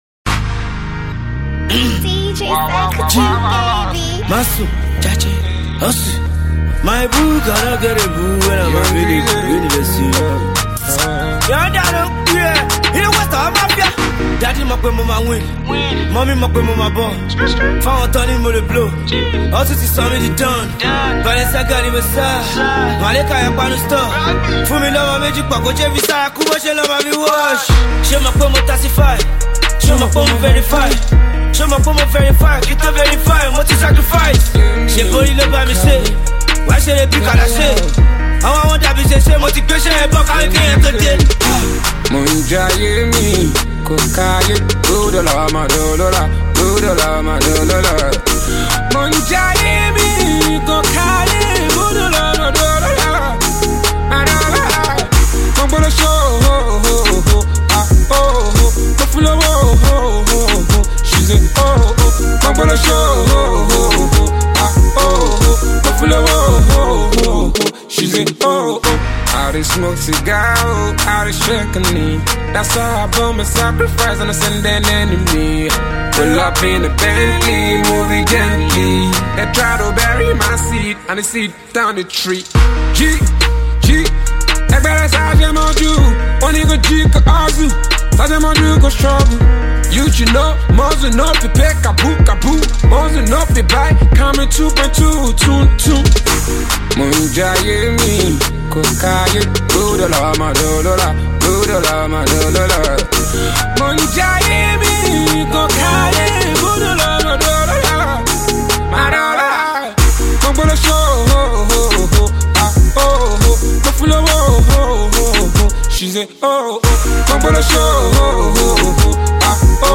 Afro-pop
Street-pop
Dancehall